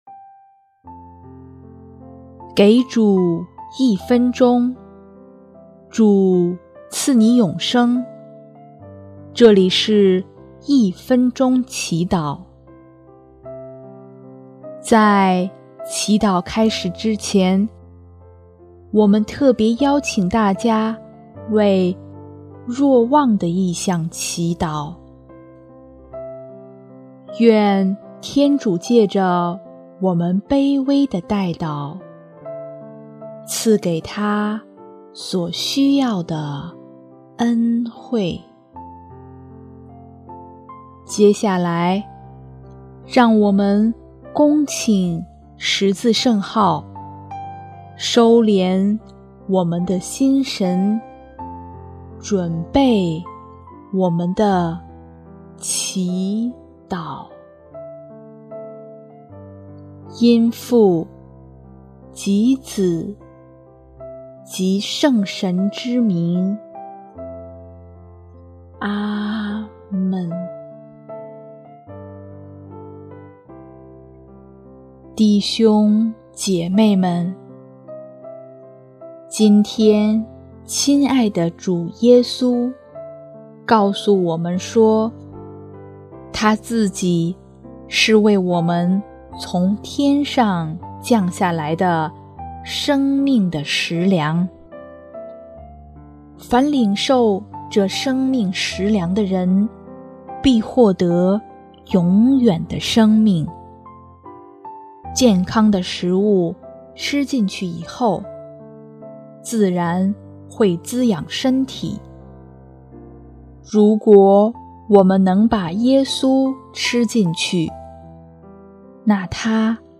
【一分钟祈祷】|6月11日 吃可见之神粮，得享永生